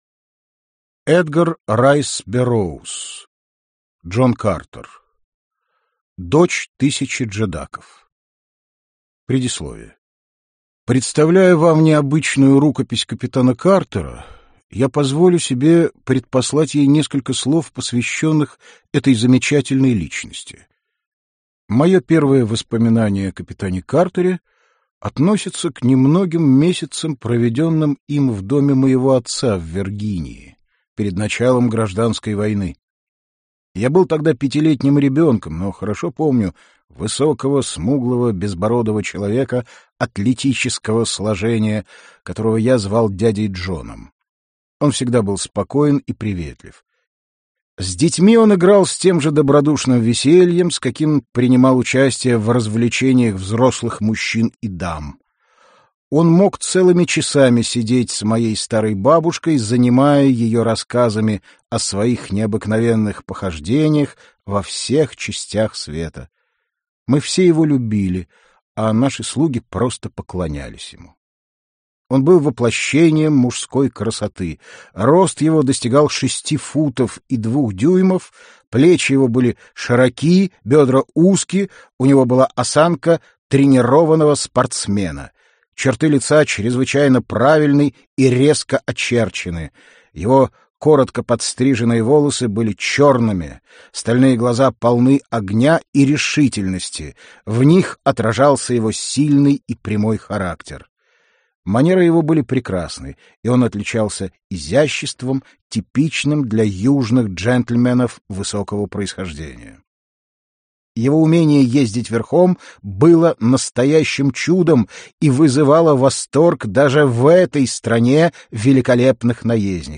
Аудиокнига Джон Картер | Библиотека аудиокниг